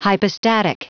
Prononciation du mot : hypostatic
hypostatic.wav